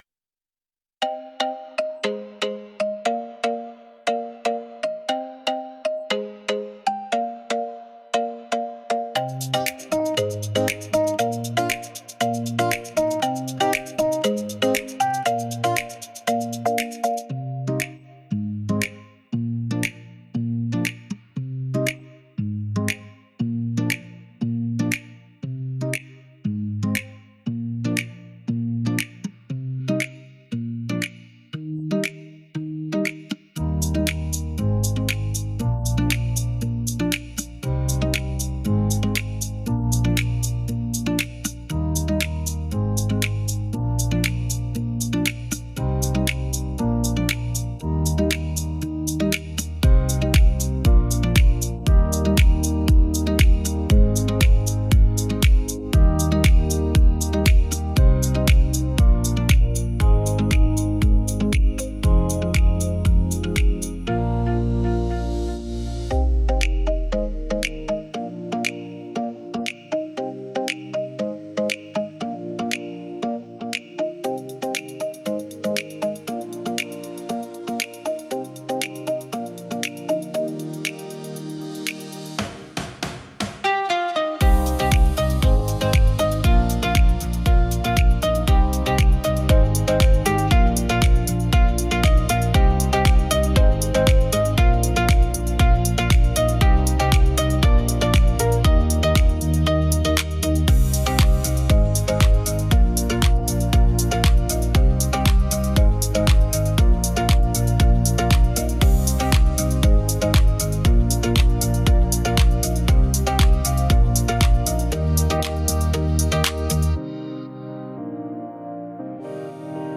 Tropical House · 118 BPM · Eng
⬇ MP3 (Instrumental) 📄 Lyrics